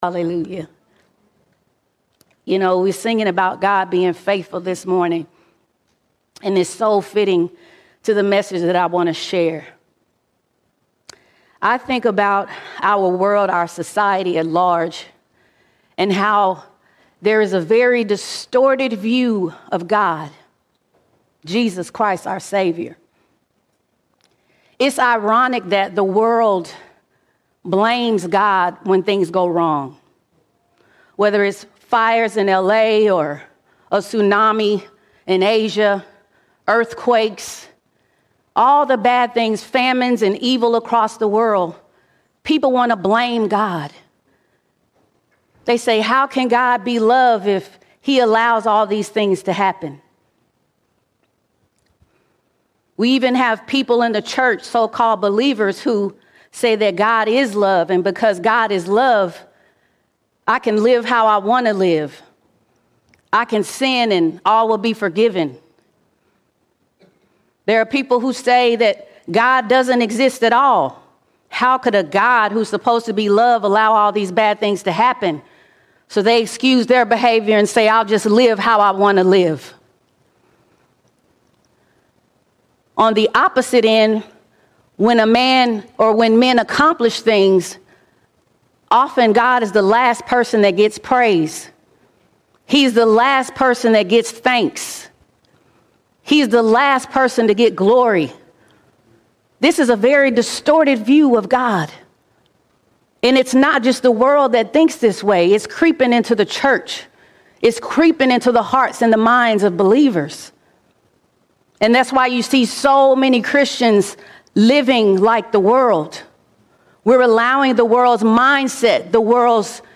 10 February 2025 Series: Sunday Sermons All Sermons LOOK TO GOD LOOK TO GOD No matter what, Look to God.